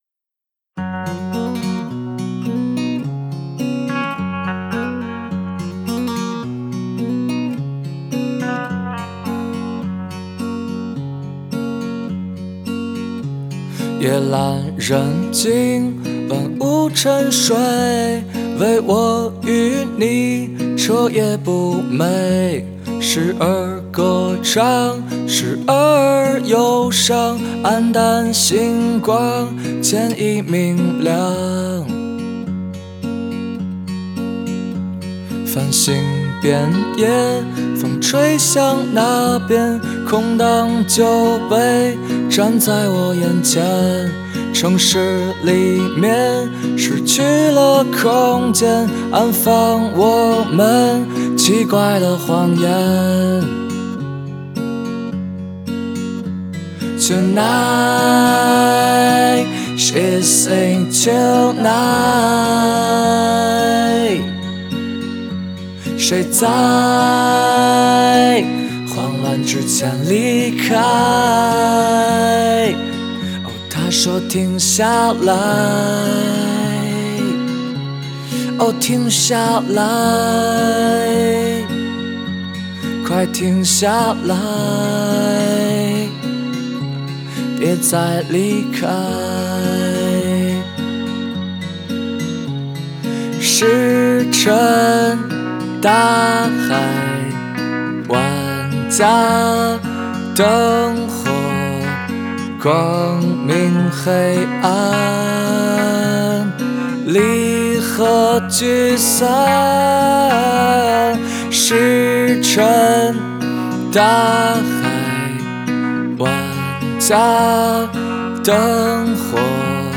中国内地摇滚乐队